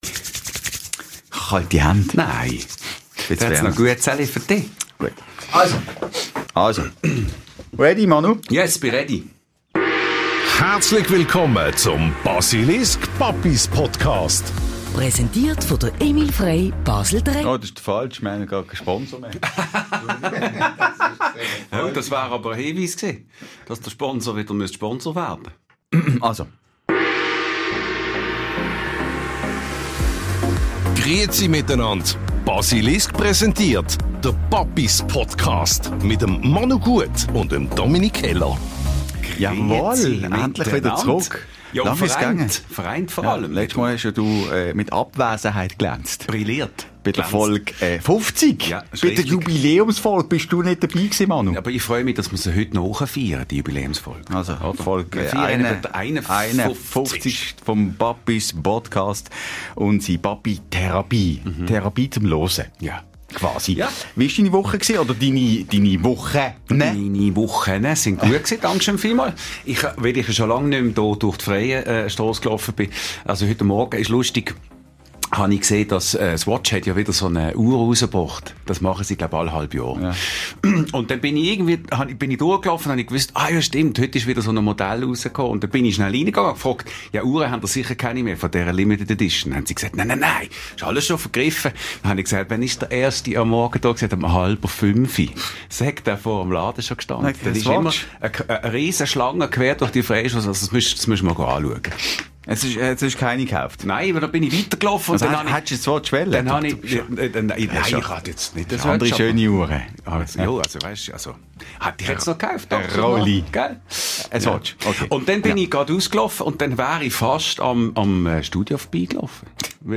Ein Weihnachtsspektakel, das glitzert, knistert und definitiv seinesgleichen sucht.